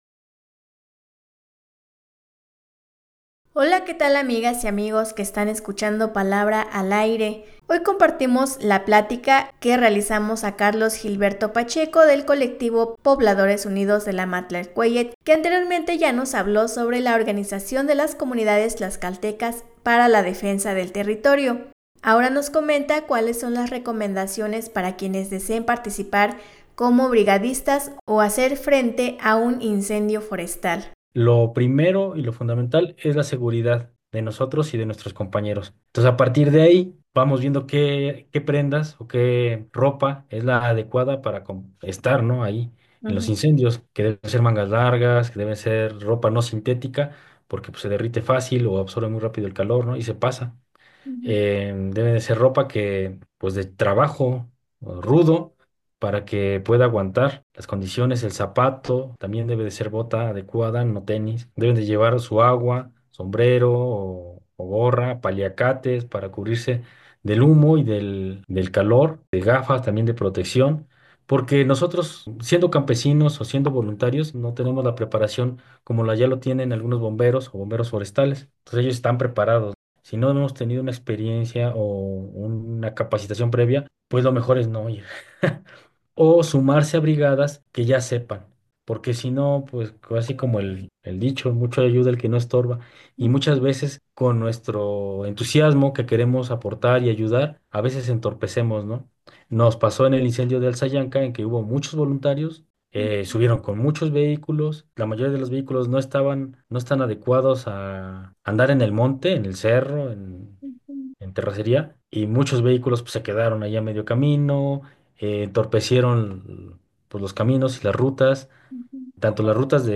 En esta plática